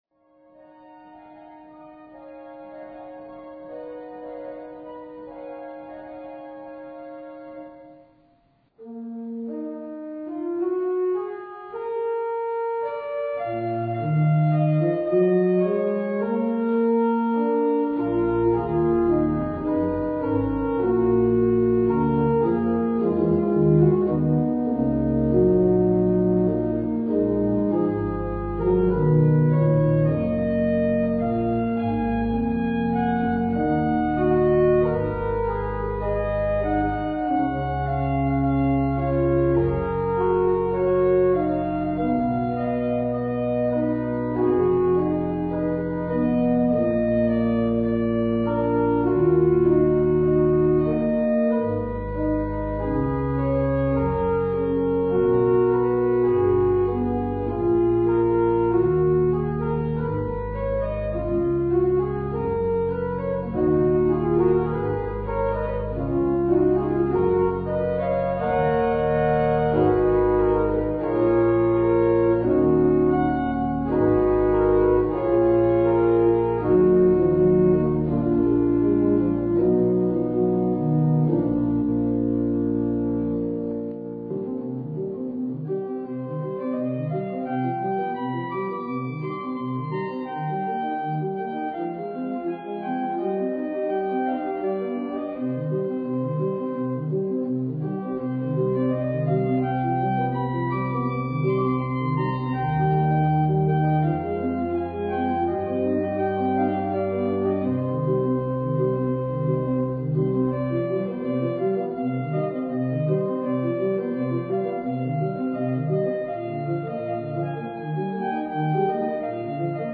Klangprobe von unserer Kirchenorgel
ein schwedisches Volkslied für